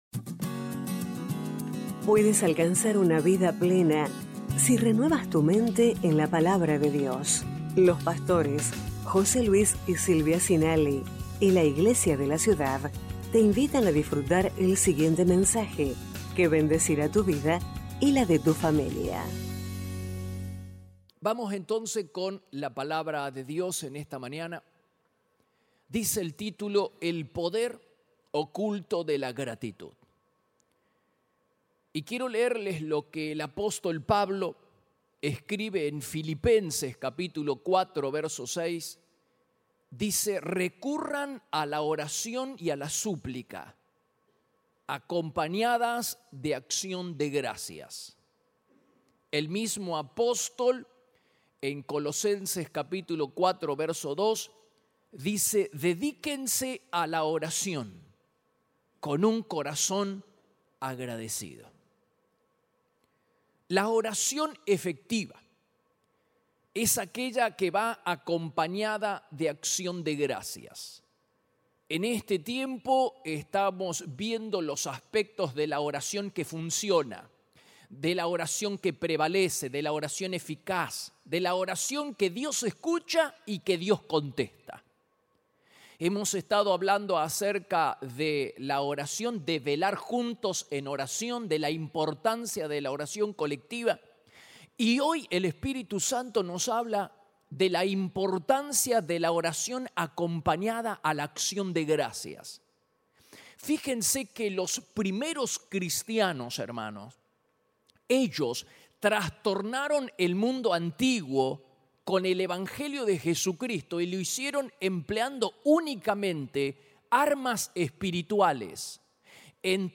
Iglesia de la Ciudad - Mensajes